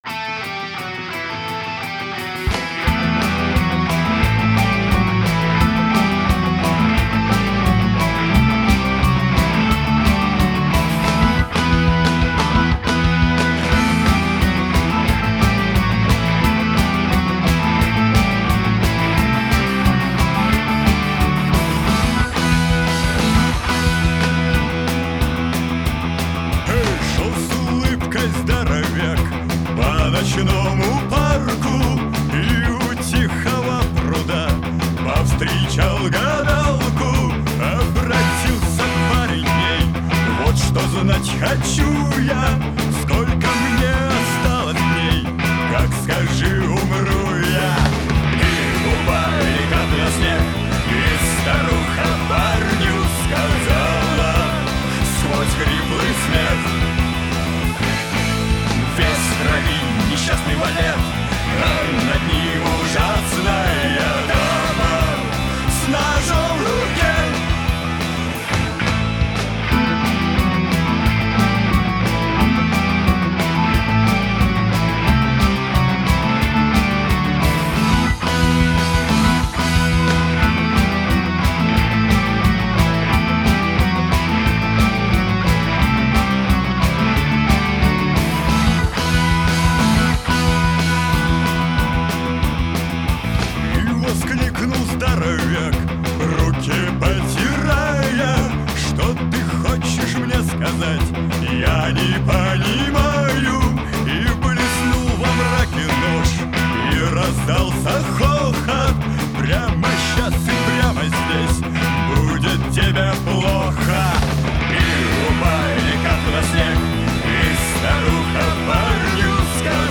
Жанр: Punk Rock